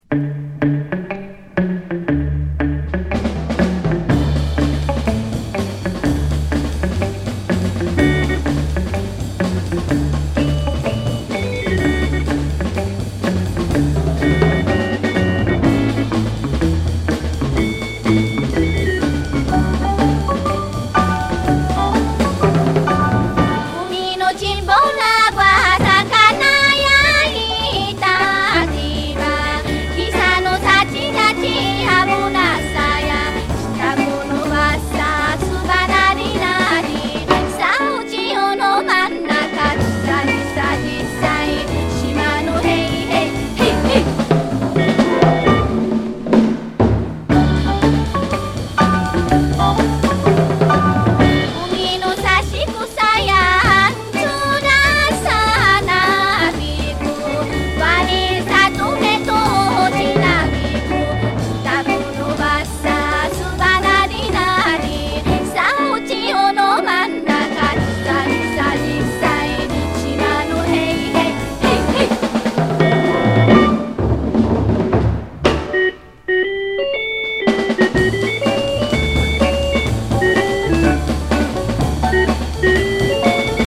沖縄伝統音楽にロックやソウルのグルーヴを施した人気コンピレーション